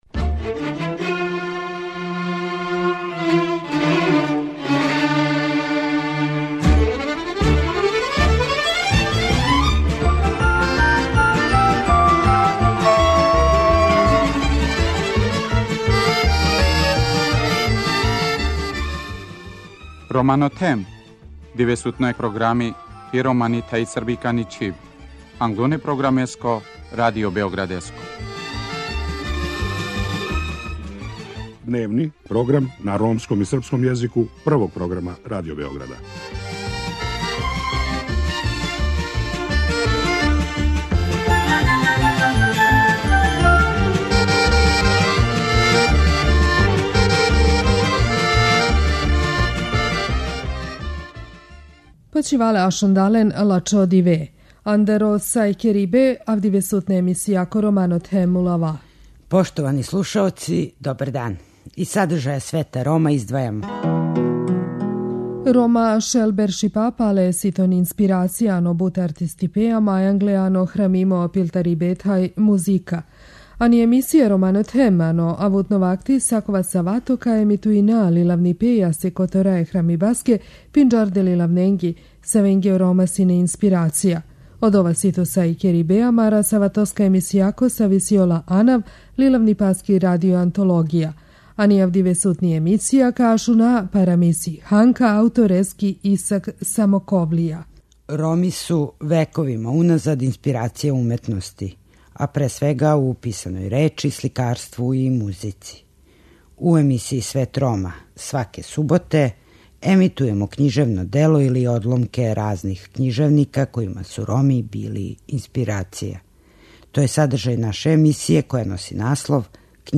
У данашњем издању слушамо приповетку "Ханка" аутора Исака Самоковлије.